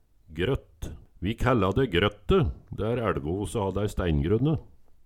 Høyr på uttala Ordklasse: Substantiv inkjekjønn Kategori: Landskap Attende til søk